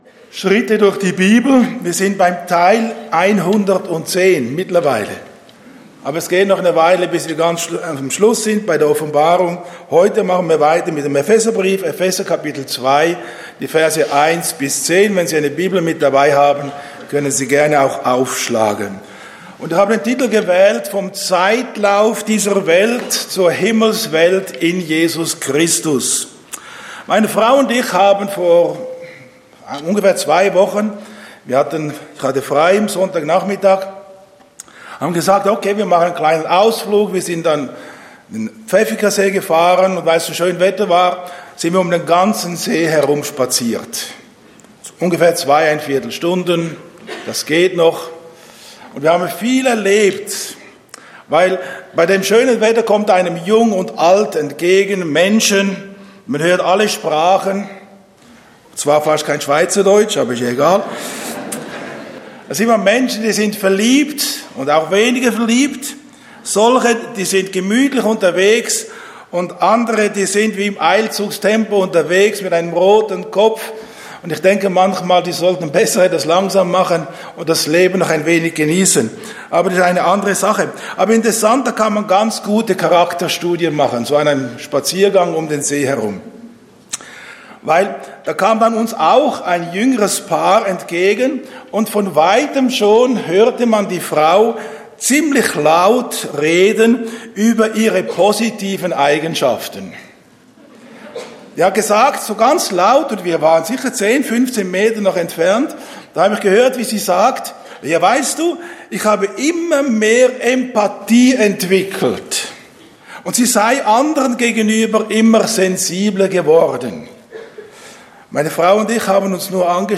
Botschaft Zionshalle